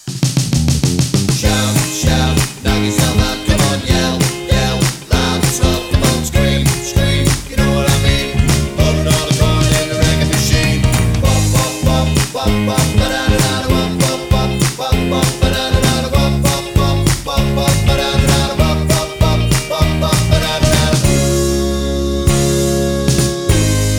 no Backing Vocals Rock 'n' Roll 2:43 Buy £1.50